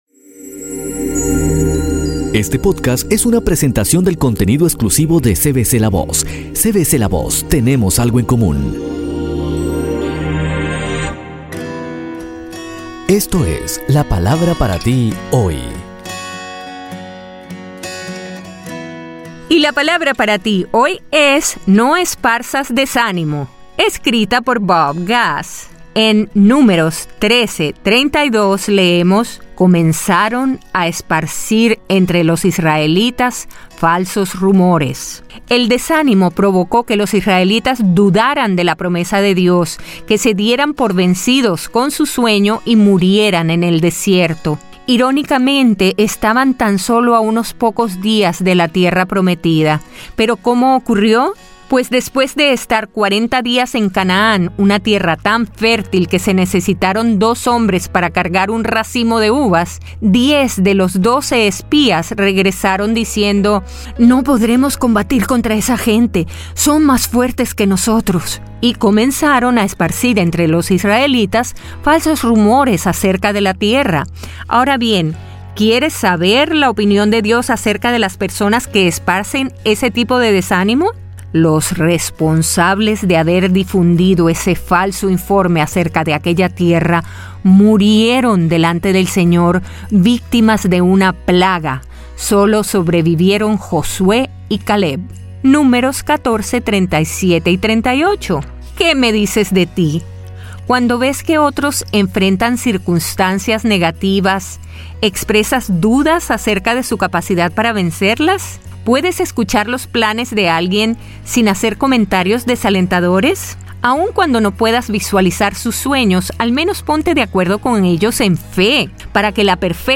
Un nuevo tema en los podcasts de los devocionales de la Palabra para ti hoy, escrito por Bob Gass y dramatizado por nuestra Elluz Peraza.